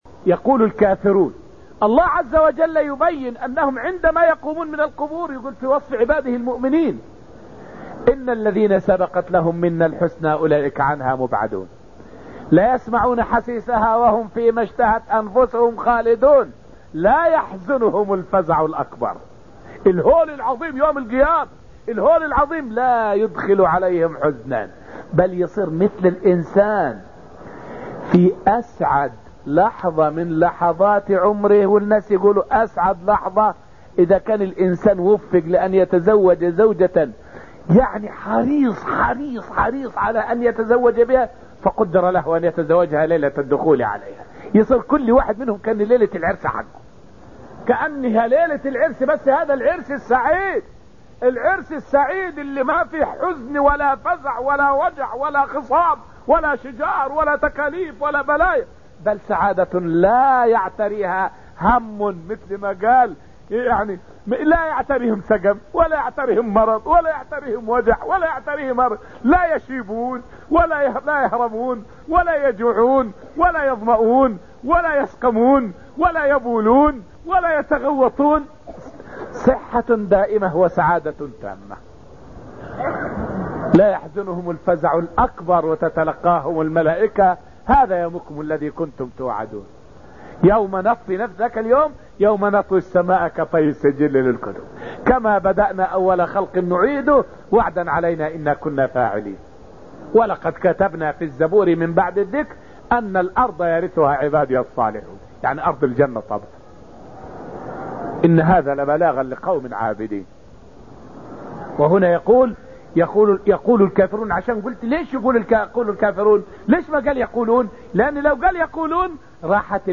فائدة من الدرس الخامس من دروس تفسير سورة القمر والتي ألقيت في المسجد النبوي الشريف حول حال النبي صلى الله عليه وسلم إذا هاجت الريح.